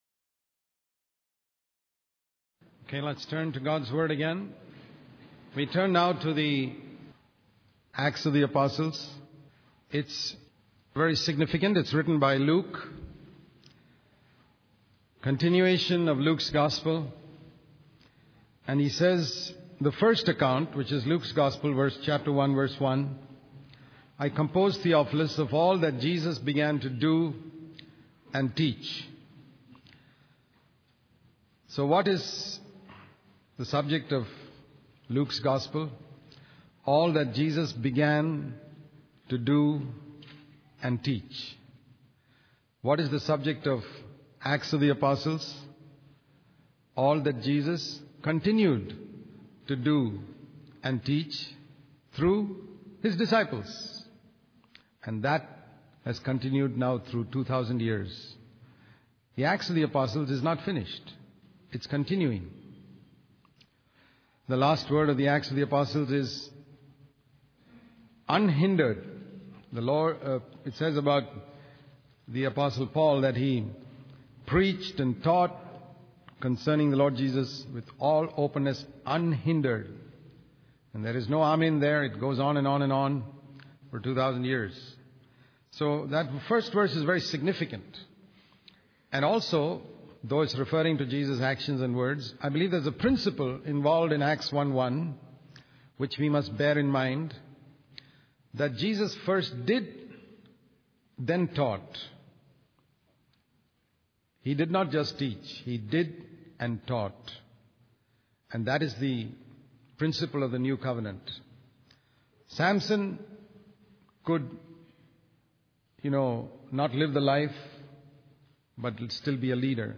In this sermon, the preacher emphasizes that God can turn the worst things that the devil does into the best things according to His plan. The preacher also highlights the importance of preaching repentance, water baptism, and baptism of the Holy Spirit in spreading the gospel. The sermon discusses a problem within the early church regarding the distribution of food, which the apostles wisely addressed by delegating the task to others so they could focus on prayer and the ministry of the word of God.